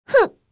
s021_Noraml_Hit.wav